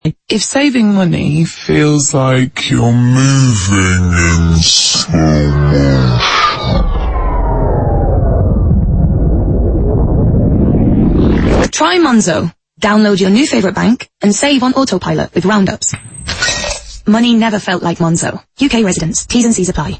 A fresh brand to radio, these ads aren’t just generic financial ads for savings accounts, they delve into how finance makes people feel. The voiceover has an approachable, youthful feel which reflects the brand perfectly.